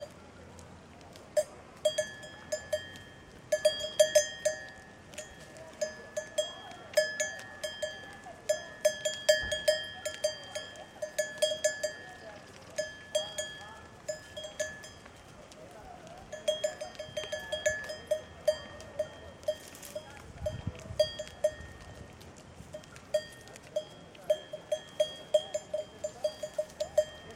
I want to share with you the excitement I feel every time I am exploring Cyprus countryside and I run into a herd of goats. It might be because I’m a Capricorn, it might also be because the sound of the goats’ bells is indeed very soothing!